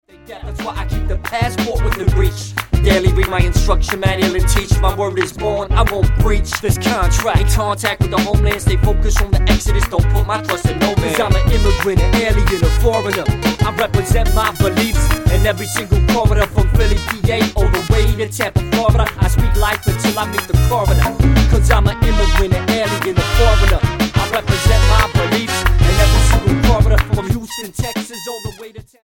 STYLE: Hip-Hop
a whirlwind Latin-laced party anthem
Very large and acoustic, just the way I like it.